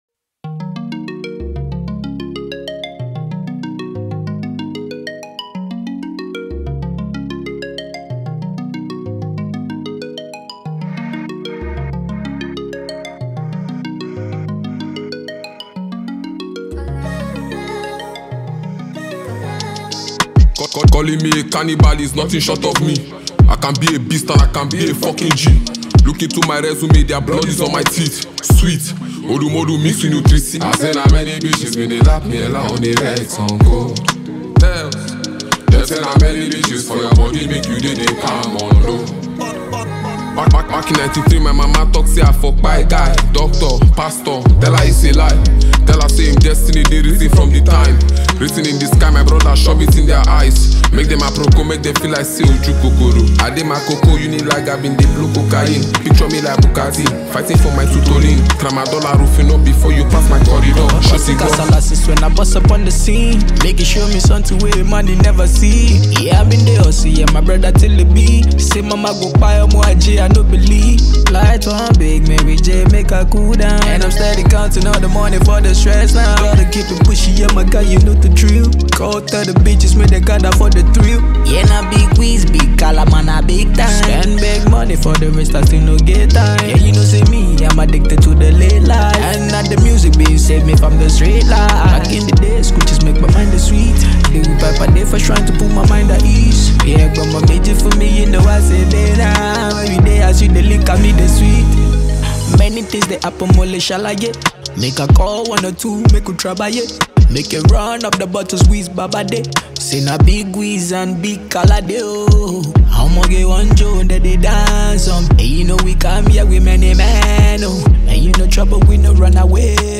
Afro pop Afrobeats